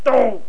My Imitation of One Type of Homer Simpson's "Doh" (.wav)